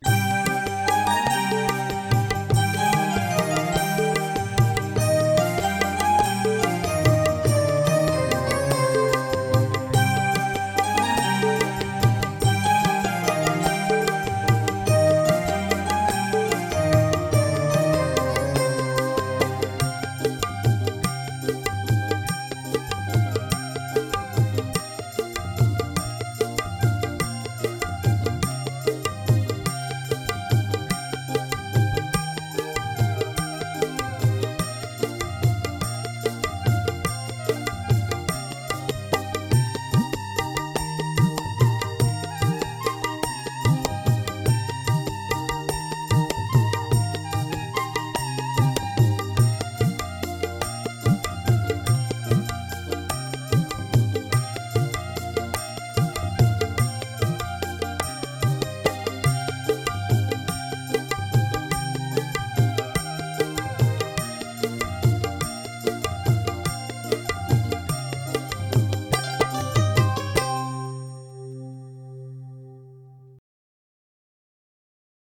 Minus One Tracks